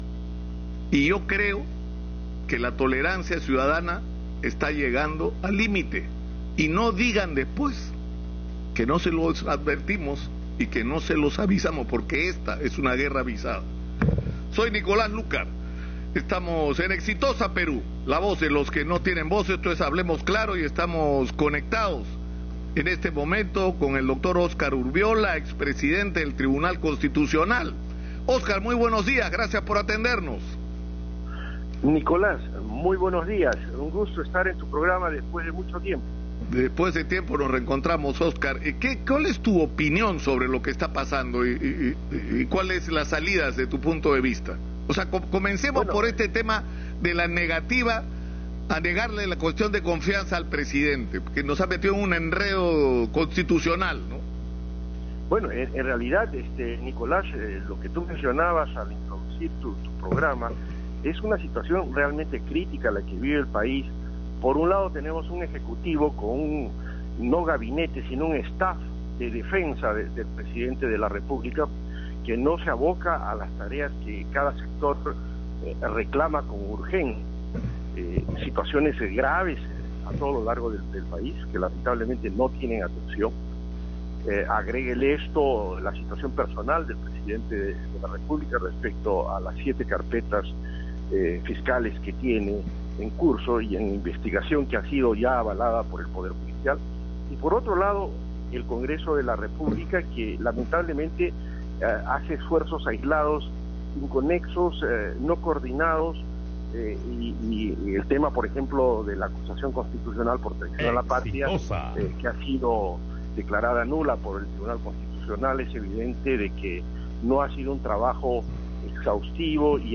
Entrevista a Óscar Urviola, ex presidente del TC